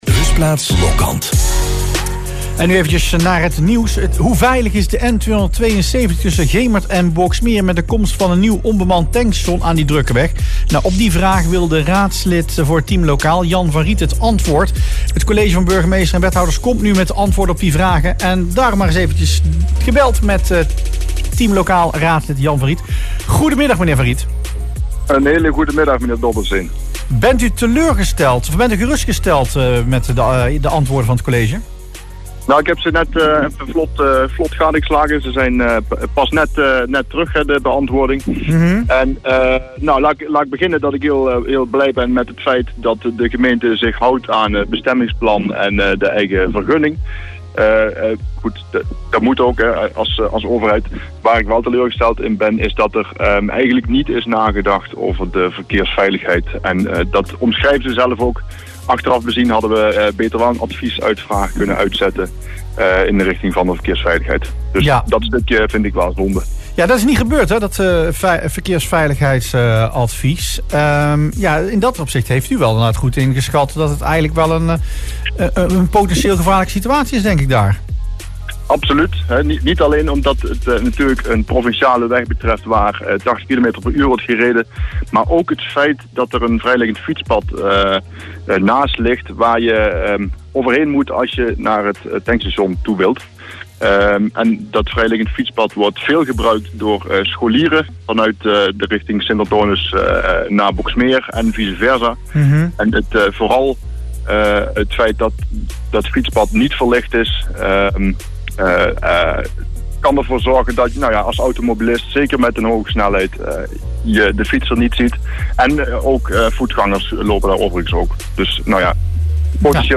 Jan van Riet in Rustplaats Lokkant